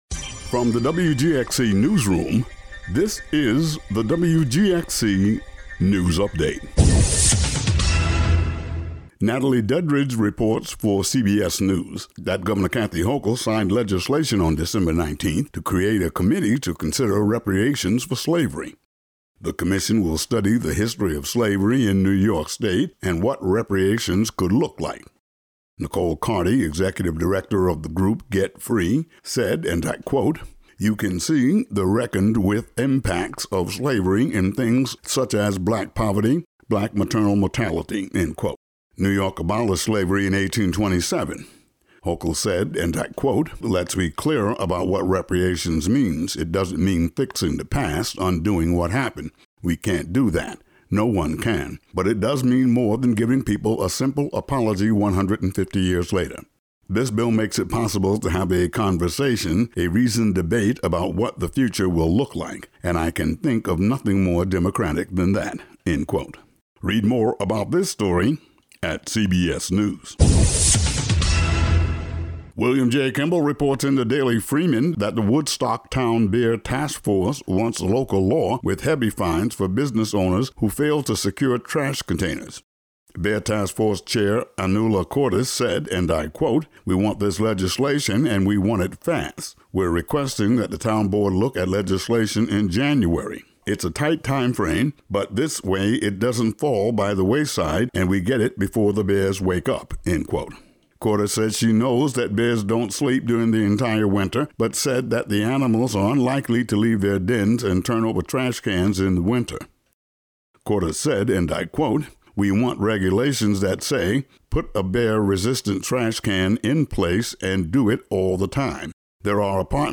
Today's daily local audio news update.